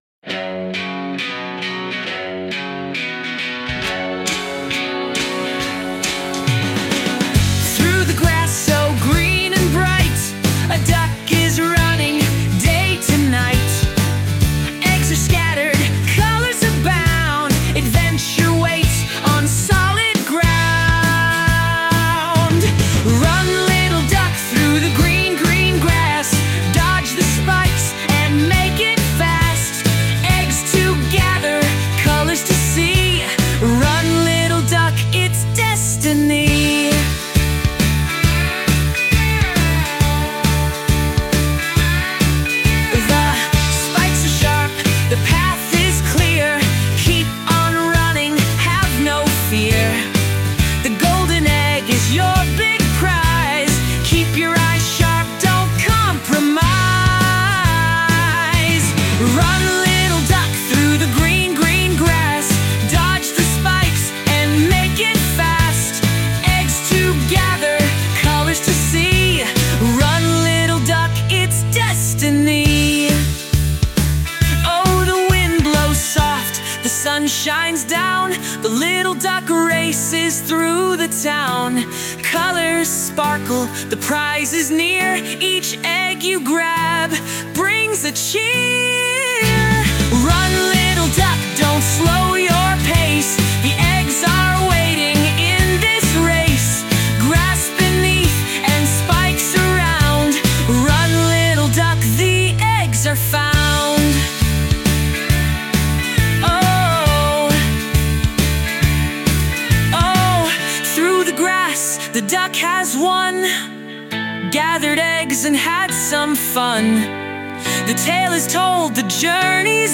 Lyrics : Written by ChatGPT
Eurovision version